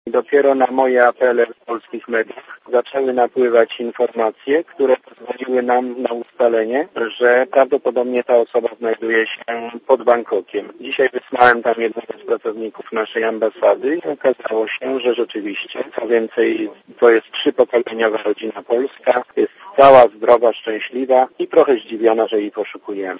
Mówi ambasador RP w Tajlandii Bogdan Góralczyk